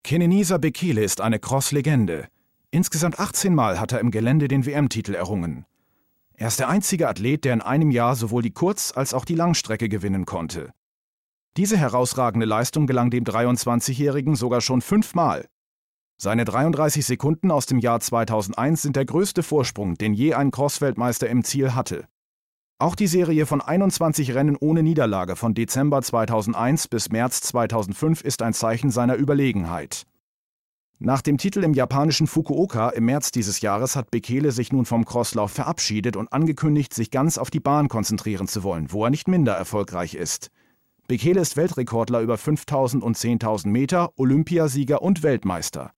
dunkel, sonor, souverän
Mittel plus (35-65)
Comment (Kommentar)